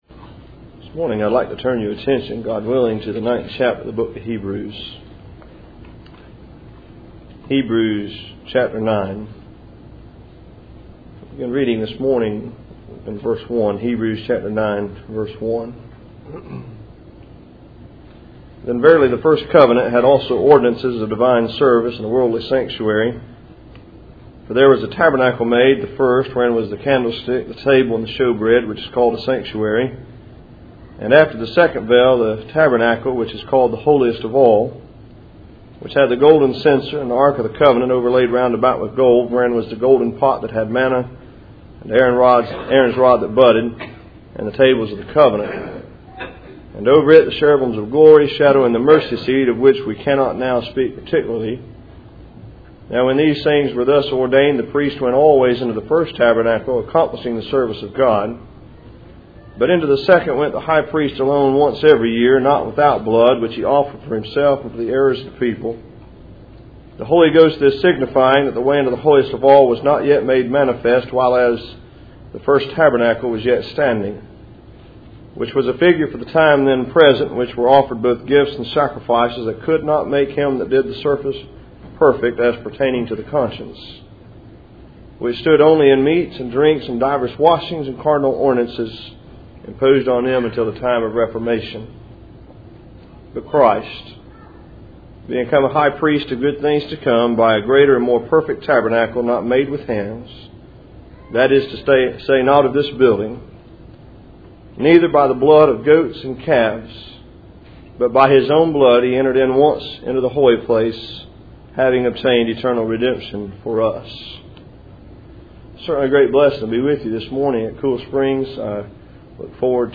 Passage: Hebrews 9:1-12 Service Type: Cool Springs PBC 1st Saturday %todo_render% « The Shepherd and the Sheep